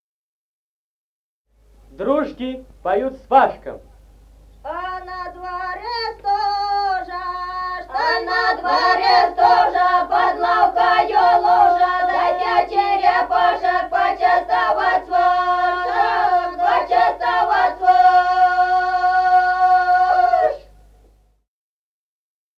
Народные песни Стародубского района «А на дворе стужа», свадебная, дру́жки поют свашкам.
(подголосник)
(запев).
1953 г., с. Мишковка.